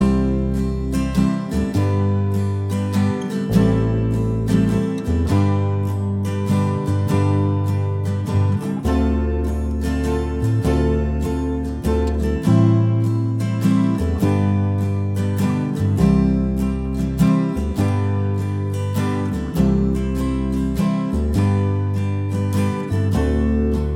Minus Mandolins Soft Rock 3:21 Buy £1.50